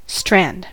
strand: Wikimedia Commons US English Pronunciations
En-us-strand.WAV